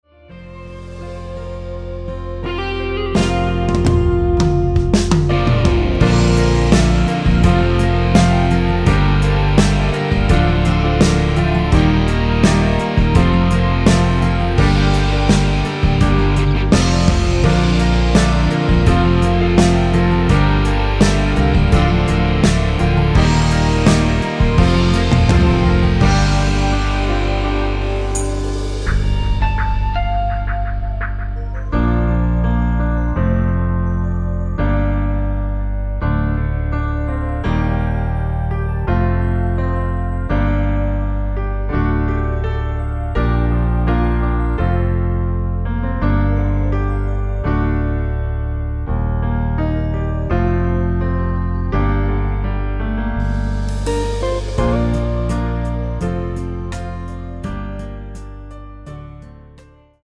Bb
노래방에서 노래를 부르실때 노래 부분에 가이드 멜로디가 따라 나와서
앞부분30초, 뒷부분30초씩 편집해서 올려 드리고 있습니다.
중간에 음이 끈어지고 다시 나오는 이유는